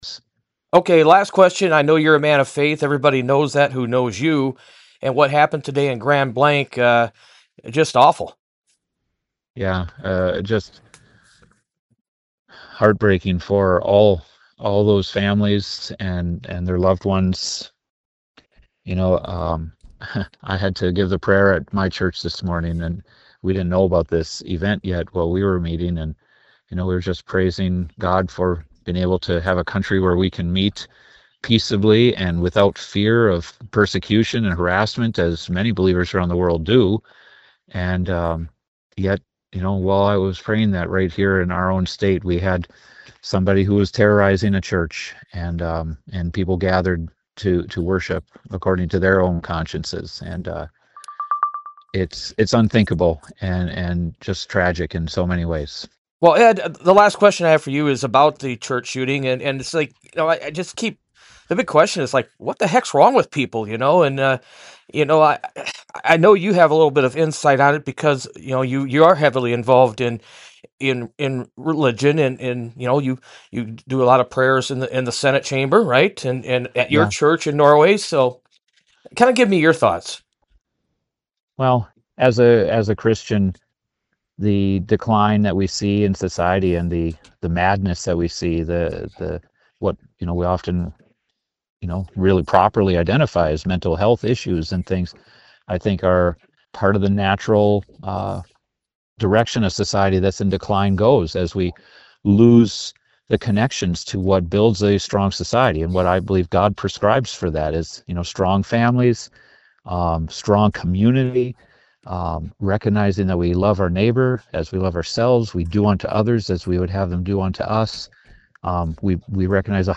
CLICK TO HEAR COMMENTS FROM U.P. STATE SENATOR ED MCBROOM (R-NORWAY)
He gave RRN News his reaction Sunday night.